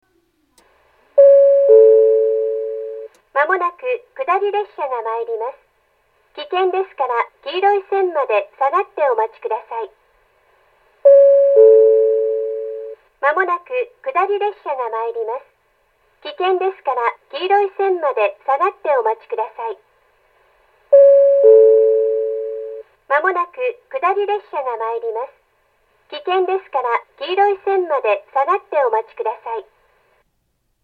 接近放送は接近表示機、発車ベルは電子電鈴、中央制御の遠隔放送はスピーカーからそれぞれ流れます。
２番線接近放送